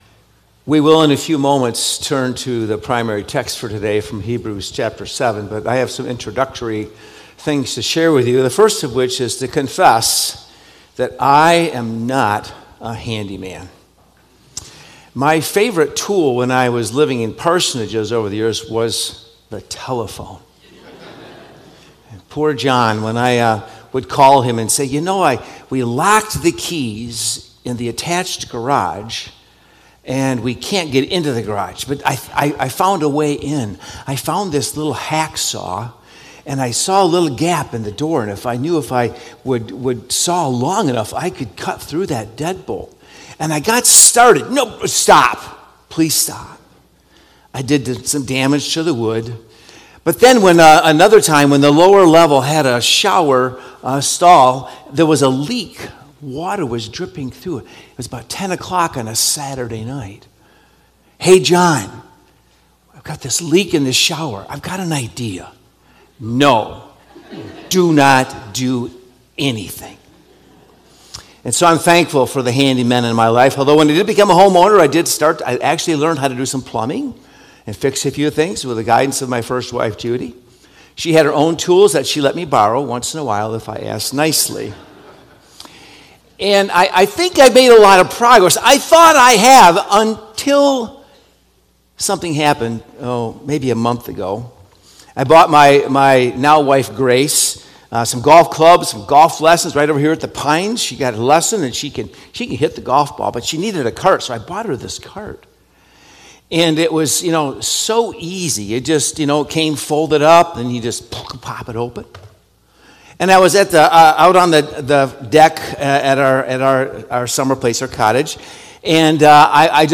Sermon Recordings | Faith Community Christian Reformed Church
“Your Best Prayer Partner” August 3 2025 A.M. Service